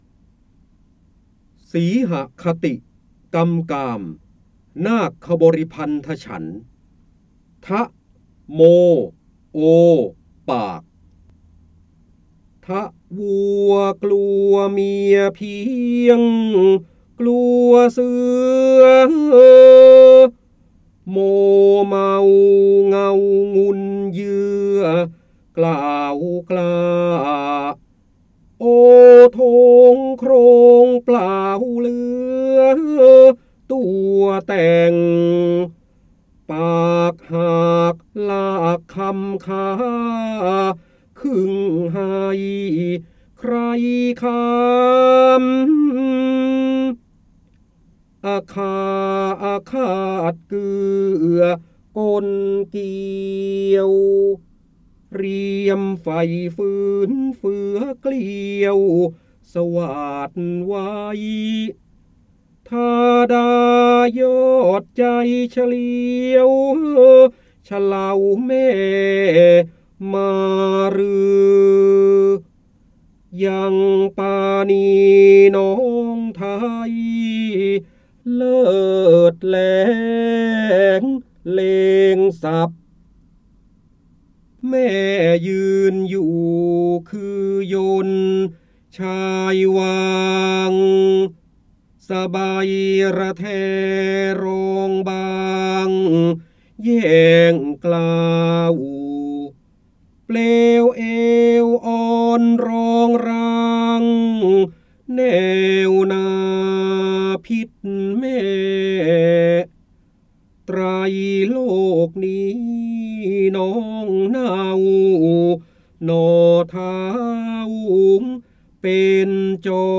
เสียงบรรยายจากหนังสือ จินดามณี (พระโหราธิบดี) สีหคติกำกาม
คำสำคัญ : พระโหราธิบดี, ร้อยแก้ว, พระเจ้าบรมโกศ, ร้อยกรอง, จินดามณี, การอ่านออกเสียง